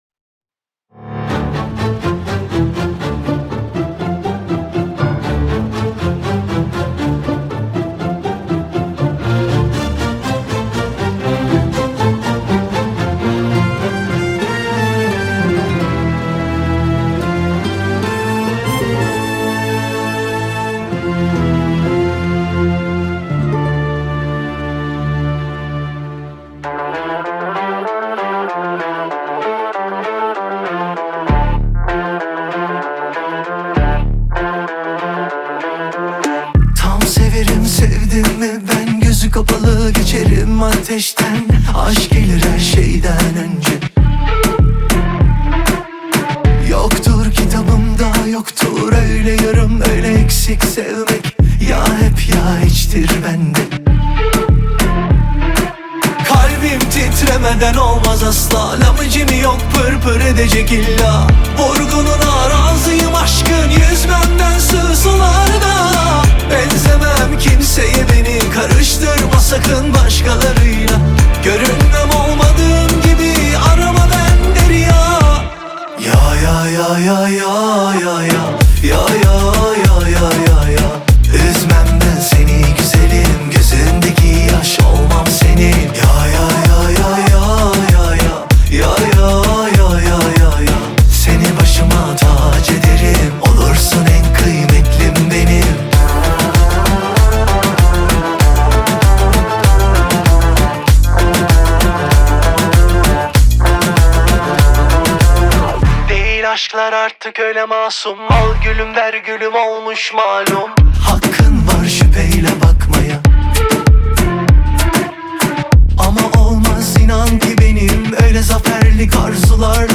Turkish Pop / Pop-Folk / Europop